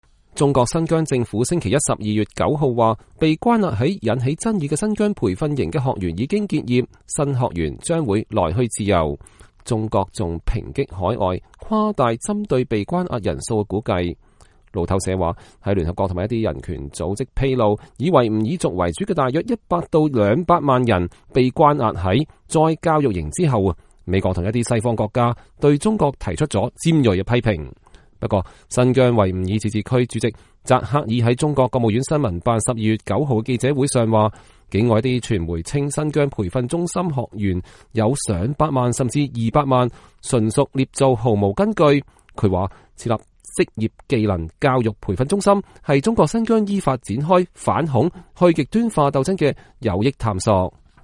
新疆維吾爾自治區主席扎克爾在北京召開的新聞發布會上講話。